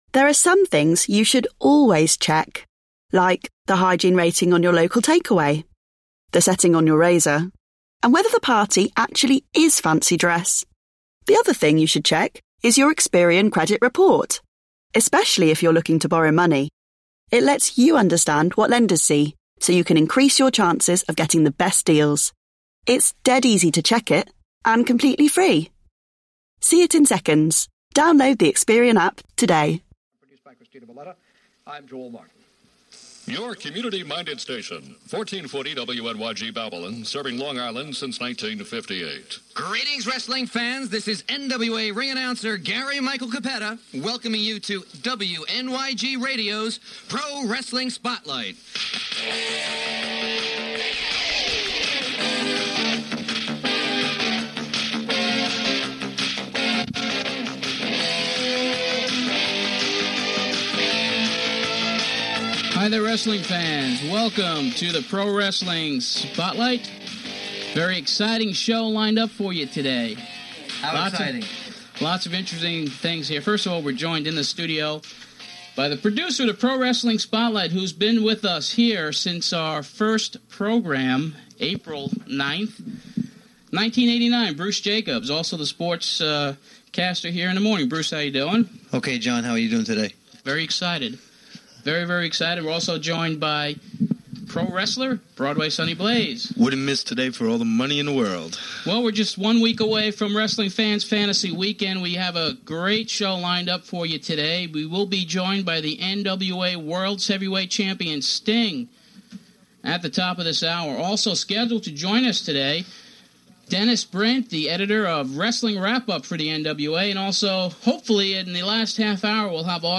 On this episode we share the original uncut broadcast of show number 72 of the Pro Wrestling Spotlight, which aired August 19th, 1990 on 1440 AM WNYG in Babylon New York.
An announcement is also made of the show moving to a larger radio station - marking this broadcast the last at WNYG. NOTE: The final 15 minutes of the program were not recorded and ends at the 45 minute mark.